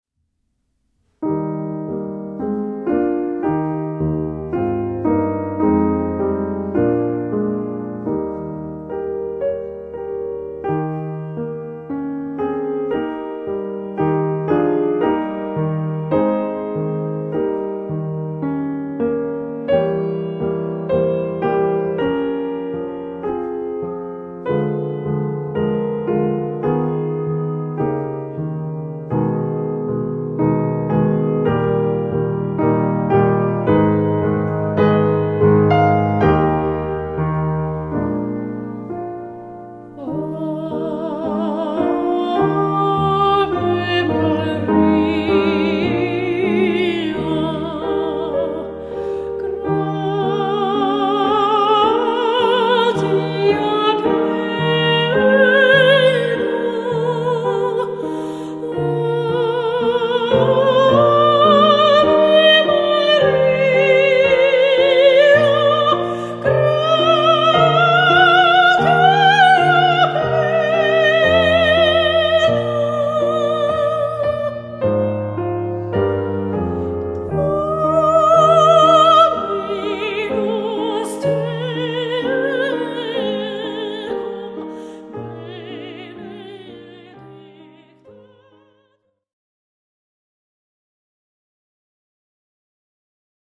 píanó